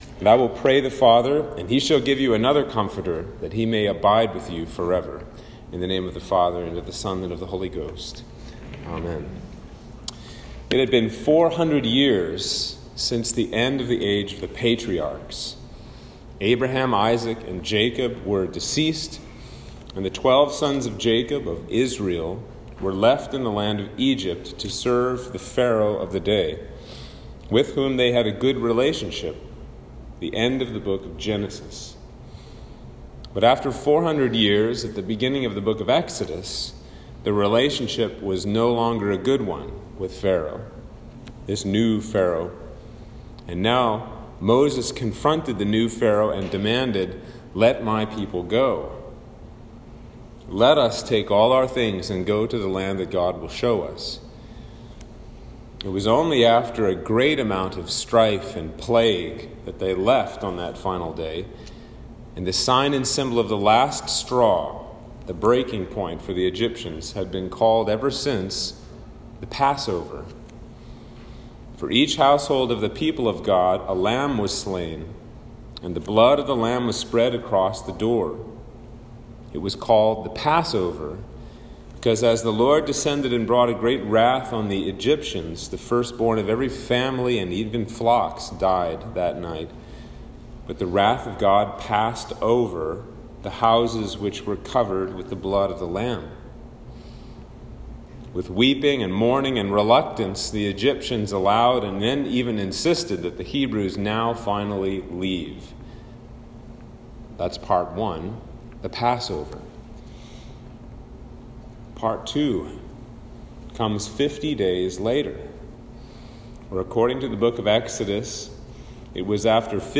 Sermon for Pentecost (Whitsunday)
Sermon-for-Pentecost-2021.m4a